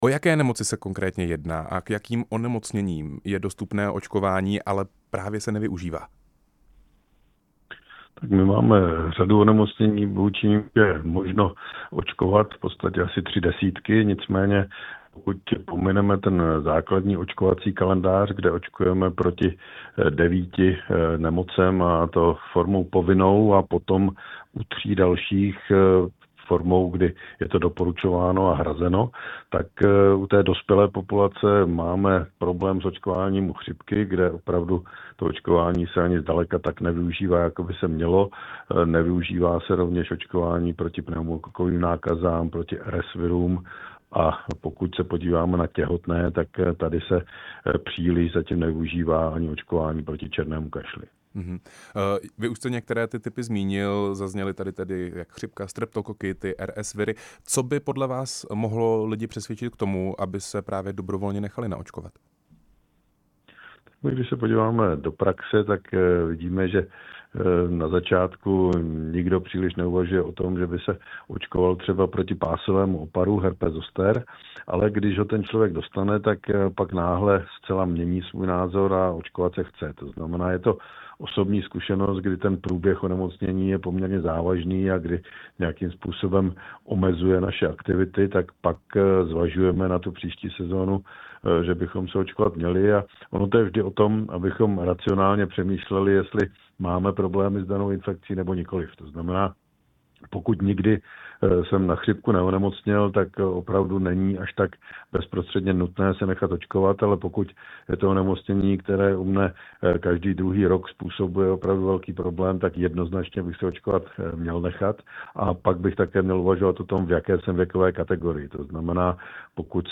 Na semináři k očkování to řekl epidemiolog a bývalý ministr zdravotnictví Roman Prymula, na téma očkování, ale i odpůrců vakcín jsme se s ním bavili také ve vysílání Rádia Prostor.
Rozhovor s epidemiologem Romanem Prymulou